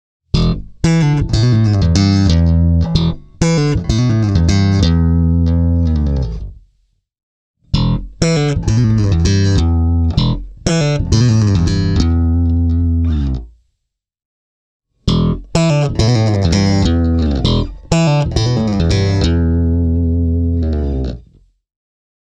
I recorded the audio clips via a Focusrite Saffirer 6 USB -soundcard straight into the audiosequencer, without any kind of amplifier modelling.
The neutral-sounding – in the best sense of the word – EMG-HZs, combined with the bass’ nice preamp, lend the Custom an air that is distinctly ”active”, with a healthy dose of presence, clarity and a fresh top end.
Here are the same bass lines as above, this time played on the Legend 4 Custom:
Spector Legend 4 Custom – slap